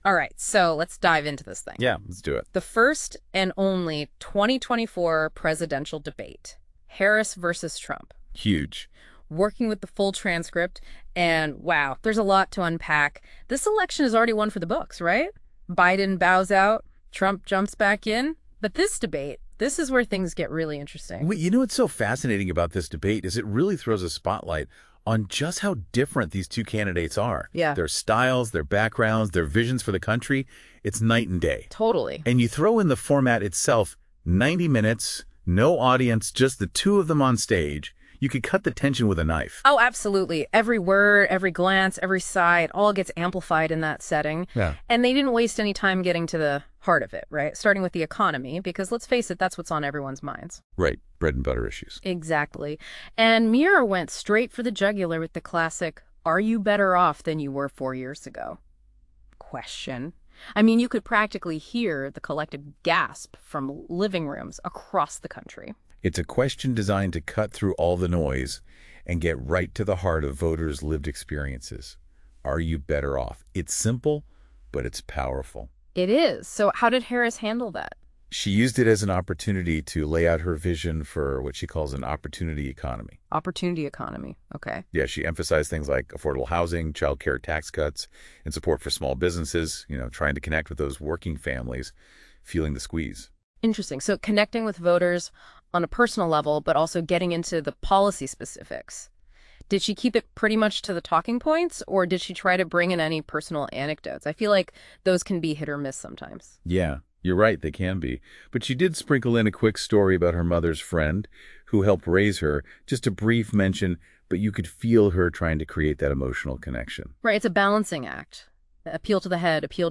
Exciting news on open-source neural voices! Our first experiment is complete with fantastic results!